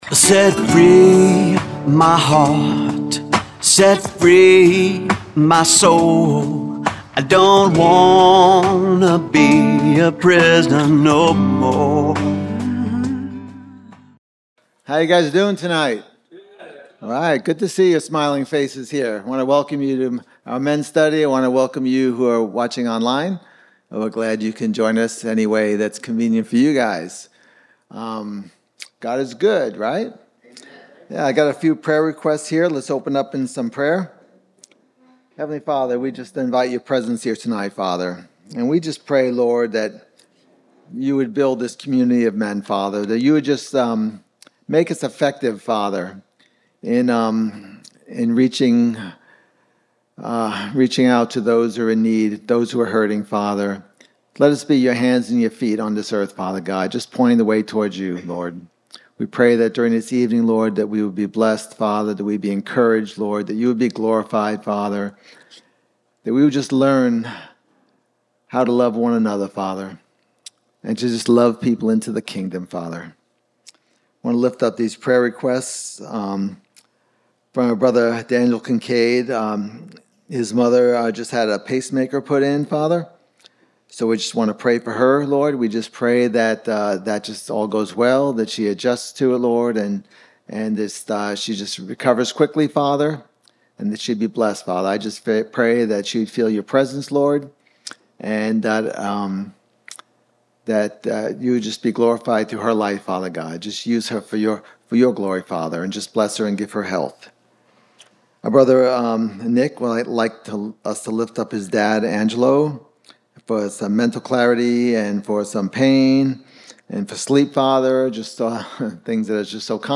Men’s Study – Page 7 – Audio-only Sermon Archive
Ecclesiastes 11 Service Type: Men's Study